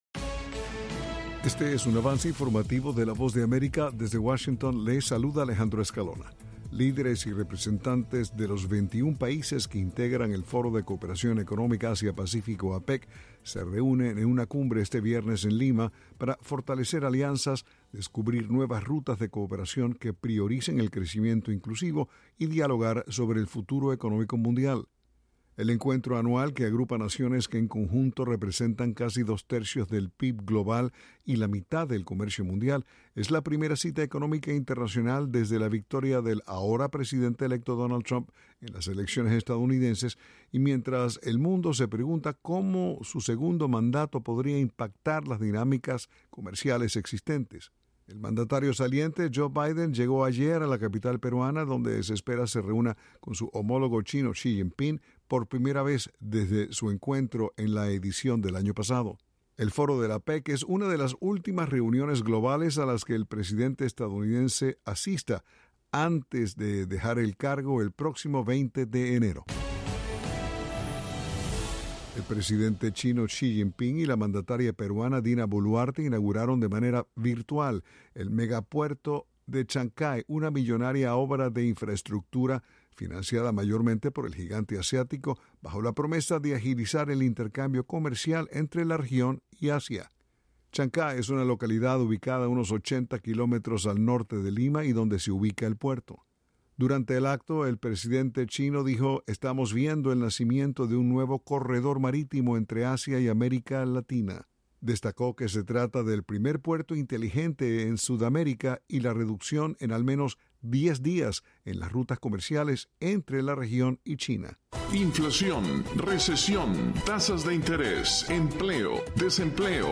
Este es un avance informativo presentado por la Voz de América desde Washington.